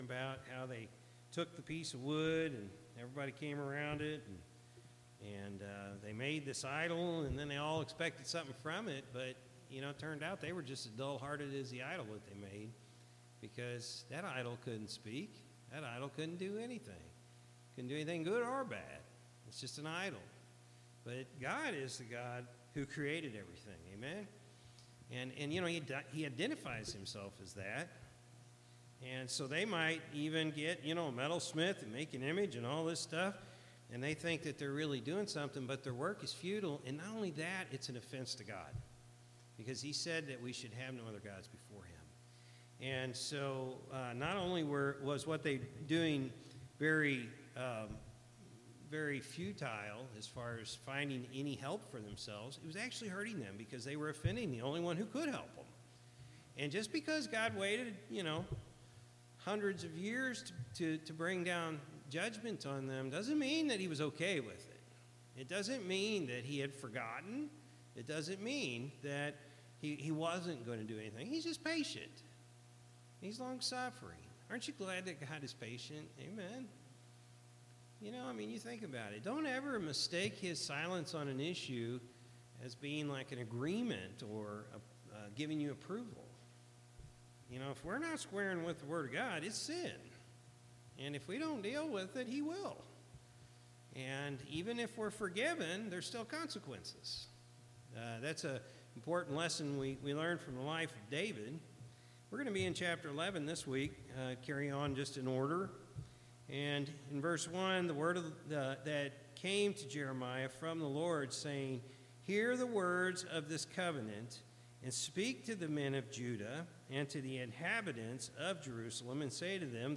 June-2-2024-Evening-Service.mp3